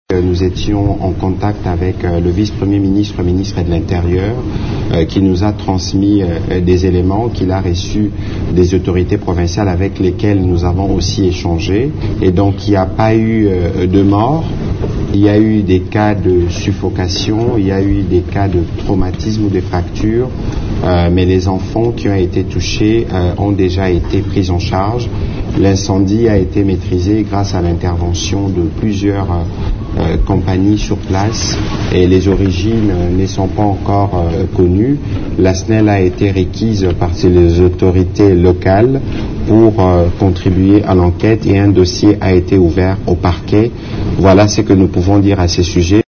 Jusqu’à présent l’origine de l’incendie n’est pas encore déterminée. Au cours d’une conférence de presse conjointement animée ce lundi à Kinshasa son collègue des Finances, Nicolas Kazadi, le porte-parole du gouvernement congolais, Patrick Muyaya, annonce que les enquêtes sont ouvertes pour déterminer les origines de cet incendie :